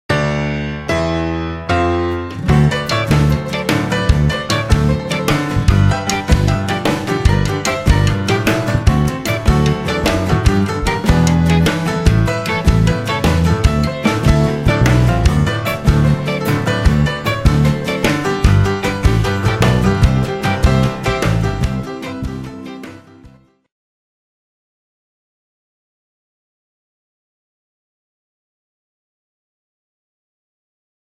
20 CLASSIC PIANO INSTRUMENTALS